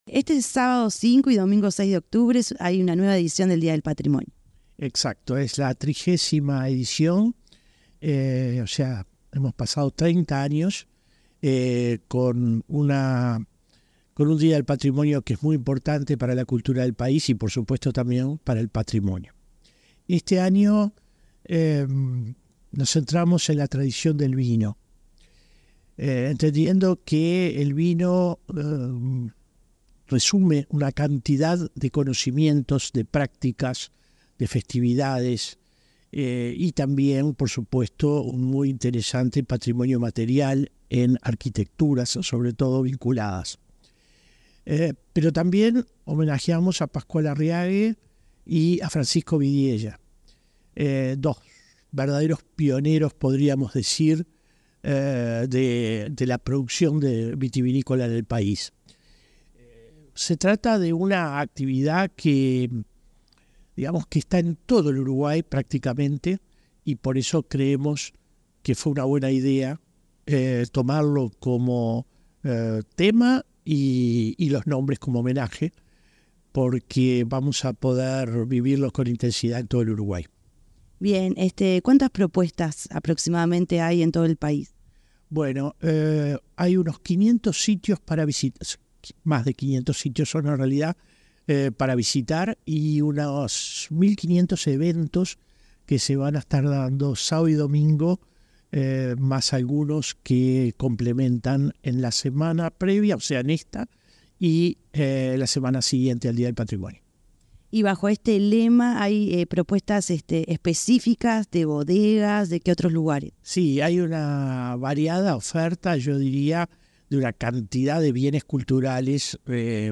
Entrevista al director de la Comisión del Patrimonio Cultural de la Nación